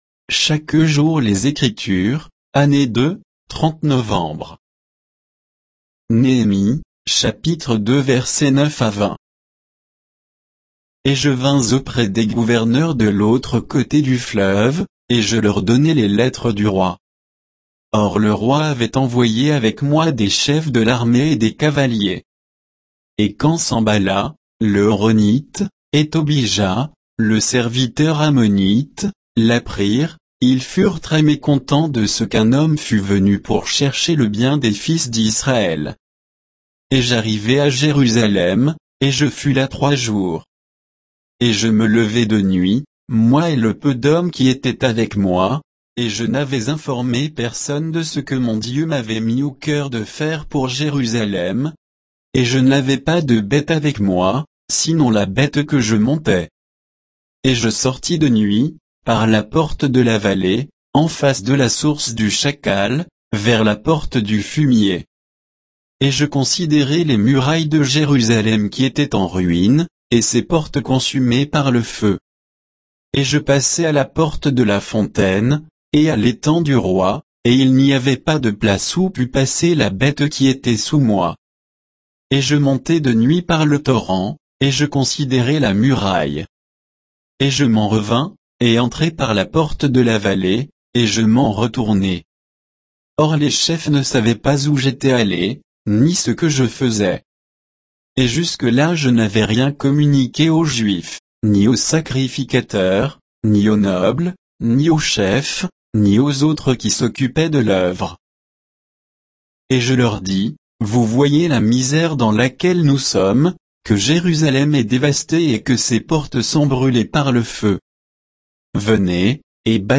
Méditation quoditienne de Chaque jour les Écritures sur Néhémie 2